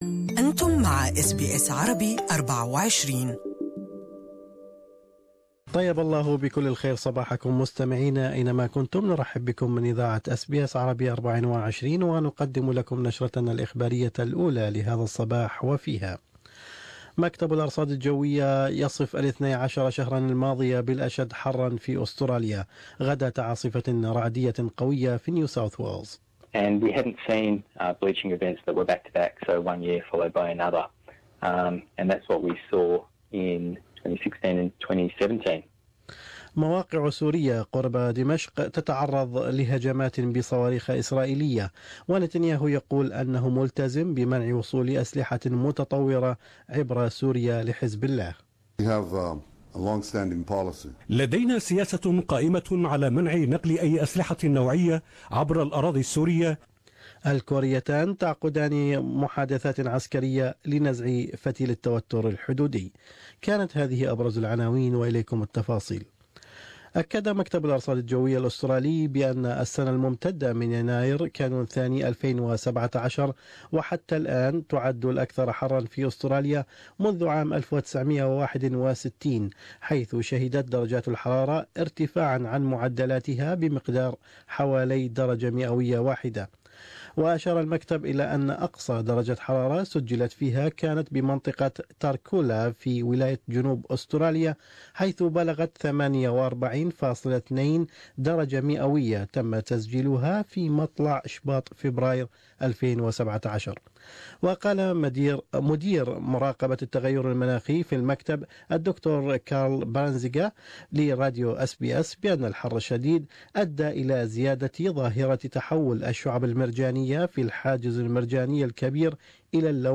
News Bulletin: Australia has had its third hottest year on record